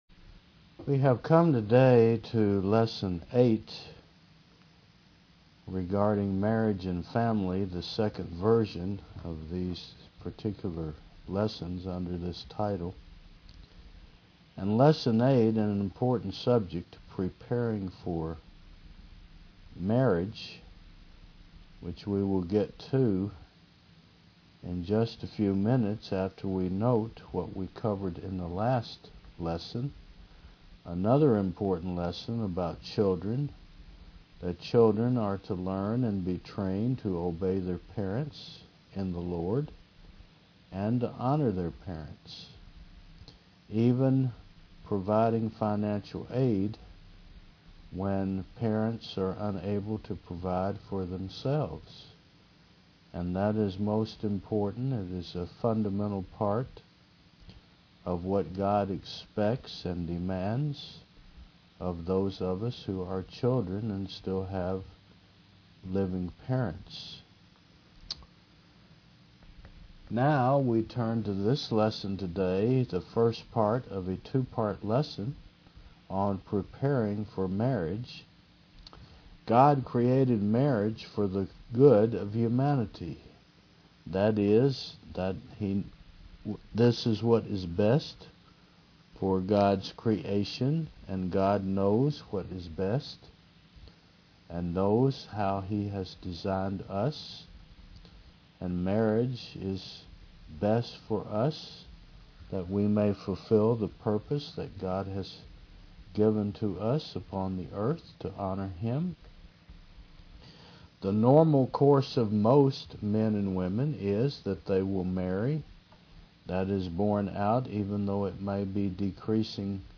Marriage and Family v2 Service Type: Mon. 9 AM The normal course for most men and women is to marry.